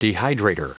Prononciation du mot dehydrator en anglais (fichier audio)
Prononciation du mot : dehydrator